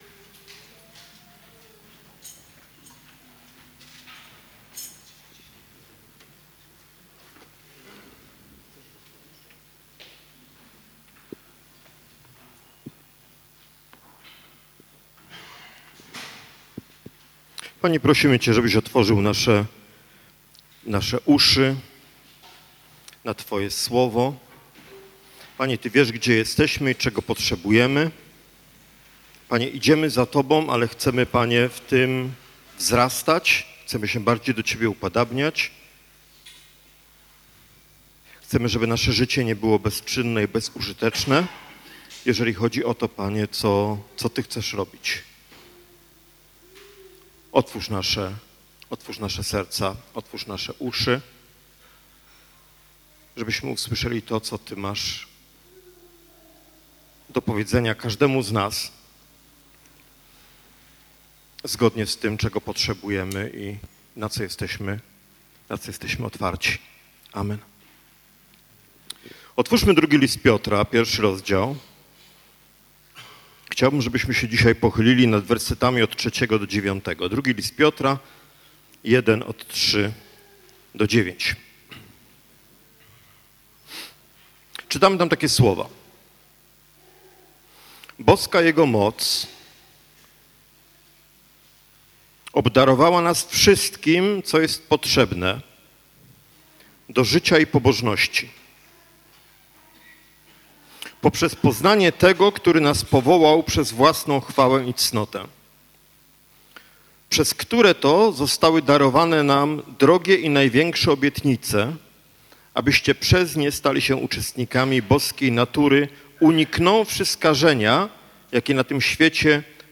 Nauczanie niedzielne